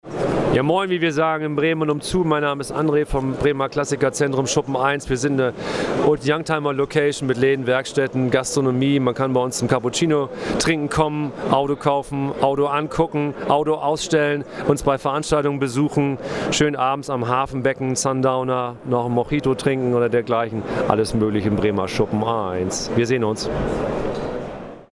Und noch ein Gruß an alle Hörer und eine Einladung nach Bremen